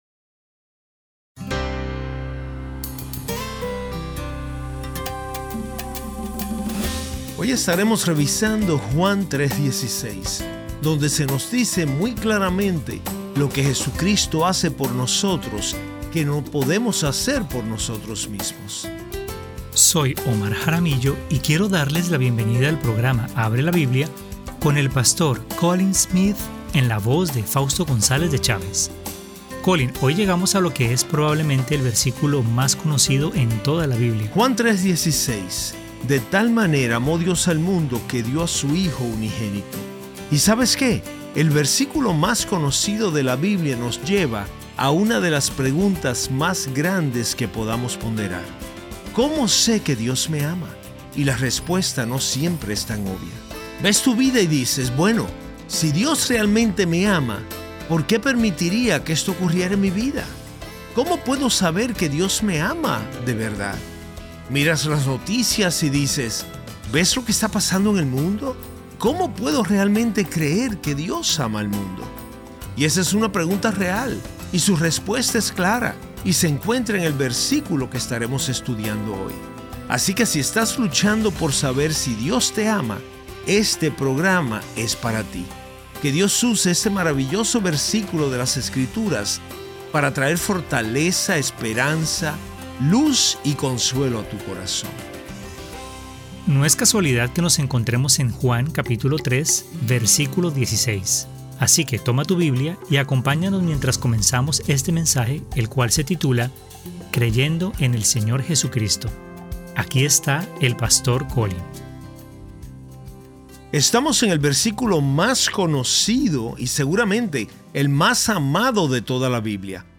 Creyendo en el Señor Jesucristo (Sermón sobre Juan 3:16) Parte 1 - Abre la Biblia